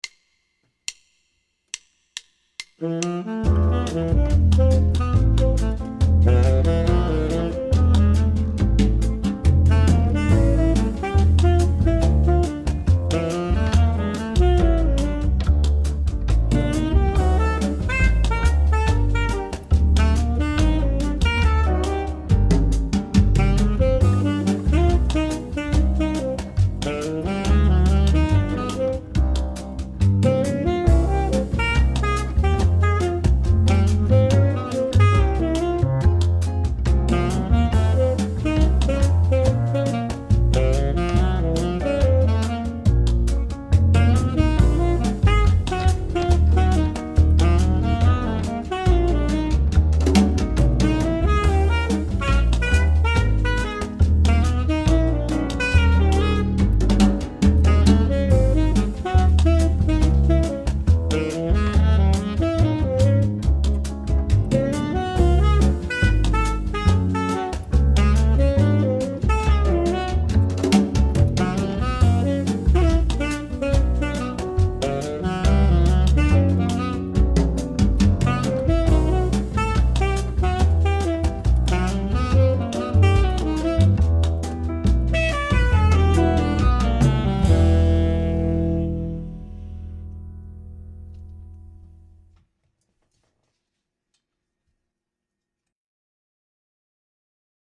Below is the a recording of the harmonic minor melody I conjured up.
mp3-mix-harmonic-minor-workout.mp3